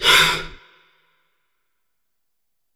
Heavy Breaths
BREATH1W-R.wav